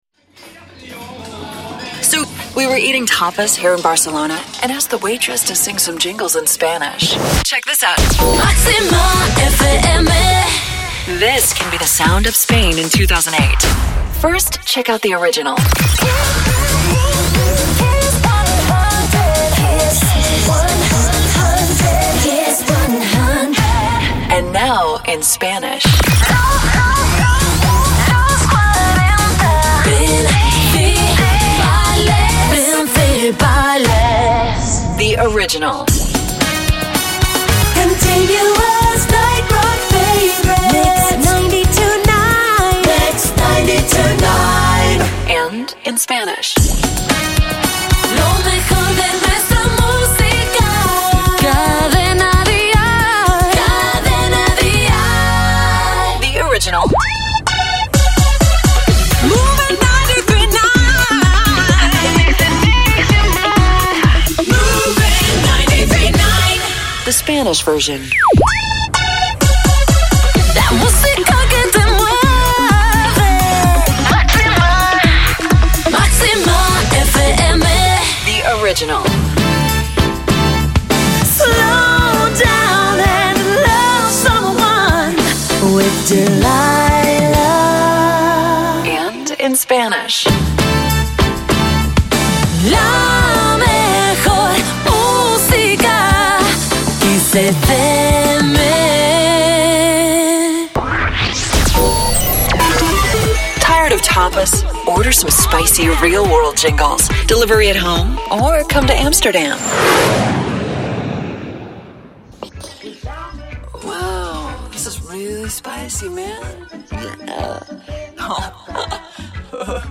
jingles
sung in Spanish